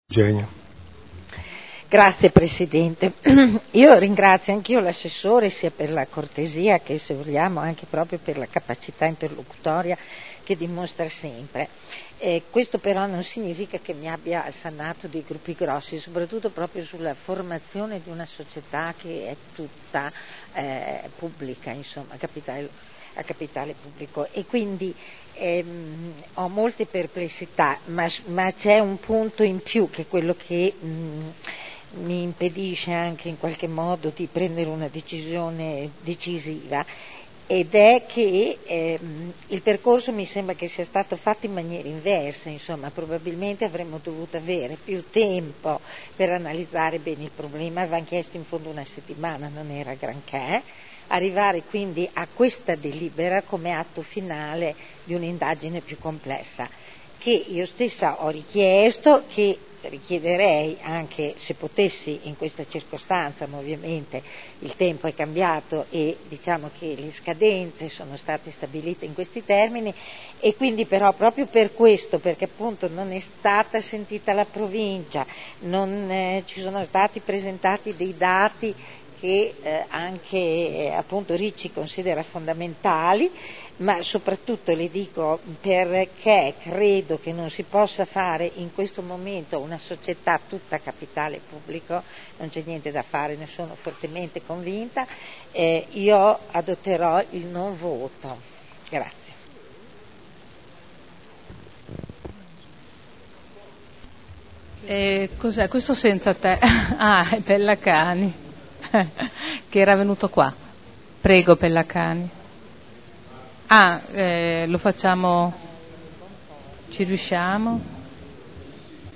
Eugenia Rossi — Sito Audio Consiglio Comunale
Seduta del 16 aprile. Proposta di deliberazione: Unificazione delle società pubbliche di formazione professionale dell’area modenese. Dichiarazioni di voto